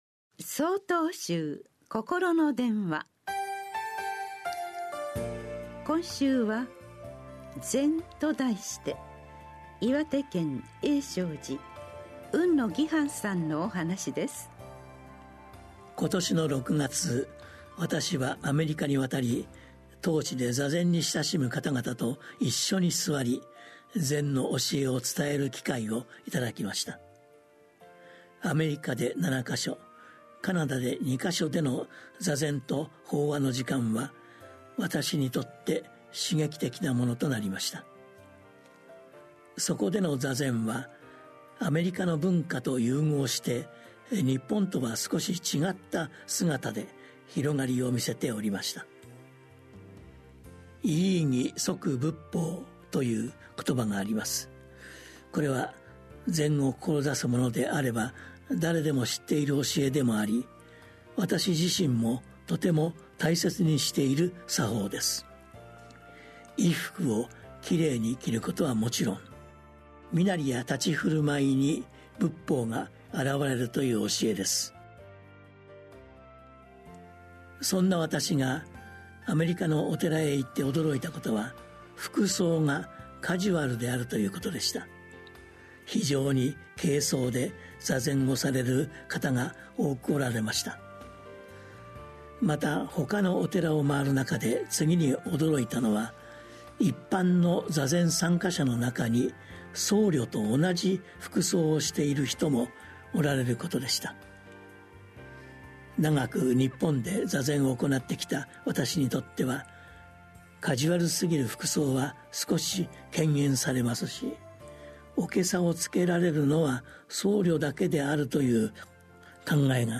心の電話（テレホン法話）９/9公開『ZEN』 | 曹洞宗 曹洞禅ネット SOTOZEN-NET 公式ページ